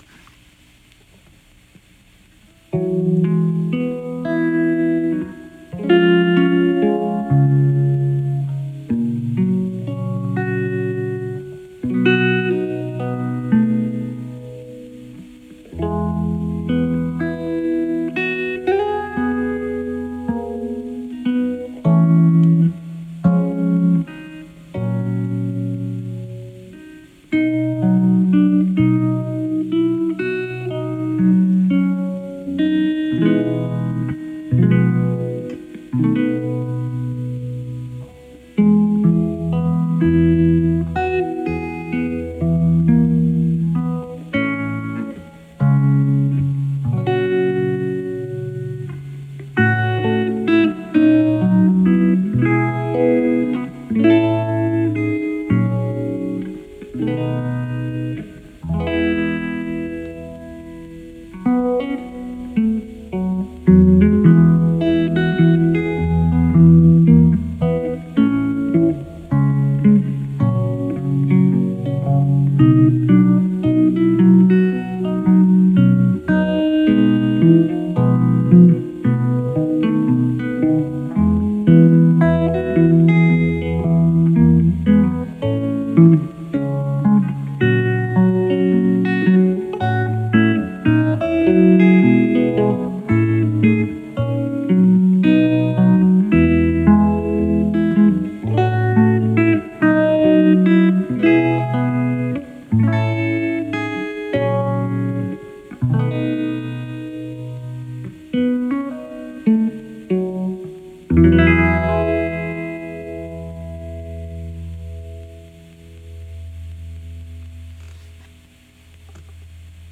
This past winter, I tried again. I finally came up with an arrangement in the key of C, but fingered with my guitar partial-capoed at the 4th fret (the 6th string left open) and thus sounding in the key of E.
I recorded this arrangement playing my Epiphone Zephyr Regent archtop electric guitar, running through an original MXR Phase 90 pedal and into my Fender ’65 Deluxe Reverb Reissue amplifier.
(For those of you surprised to hear a recording of mine sounding like that, well, I’ve been listening to Jazz guitarist Bill Frisell quite a bit over the past year.)
let-me-call-you-sweetheart-electric.m4a